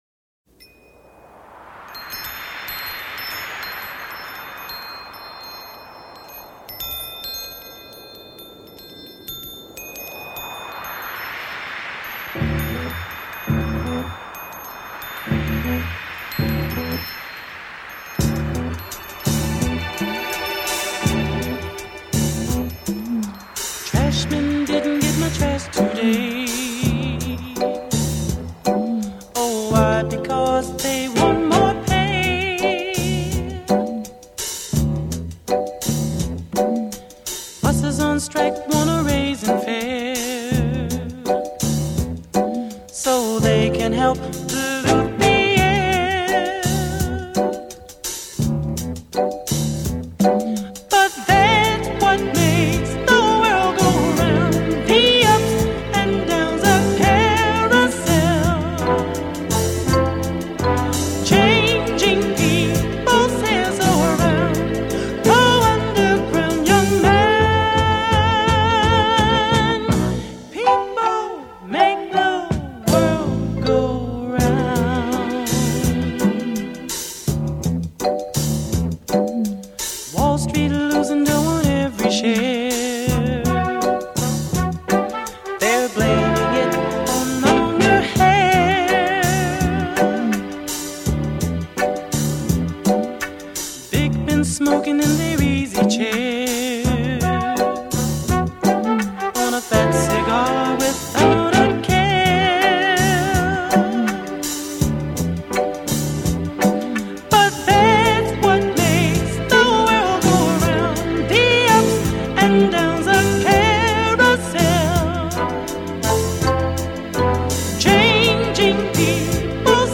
70s Philly soul music